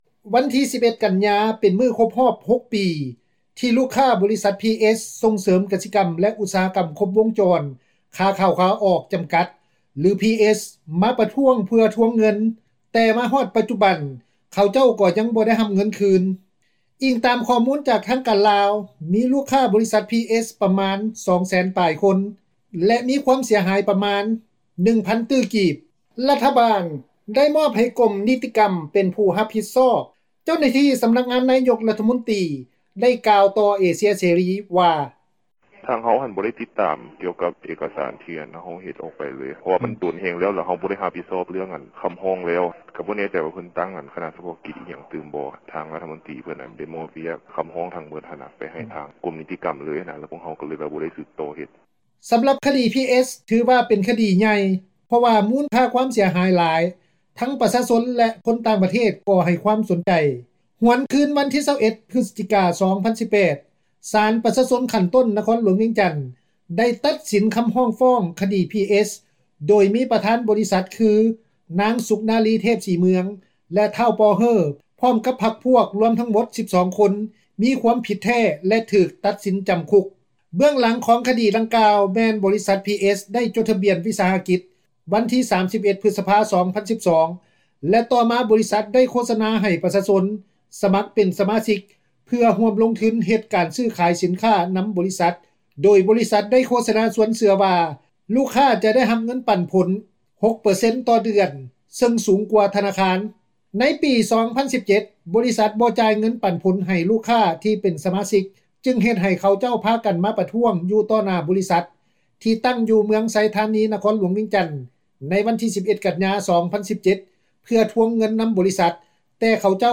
ເຈົ້າໜ້າທີ່ ສໍານັກງານນາຍົກ ຣັຖມົນຕຣີ ໄດ້ກ່າວວ່າ:
ເຈົ້າໜ້າທີ່ກະຊວງອຸດສາຫະກັມ ແລະ ການຄ້າ ໄດ້ກ່າວຕໍ່ວິທຍຸ ເອເຊັຽເສຣີ ວ່າ: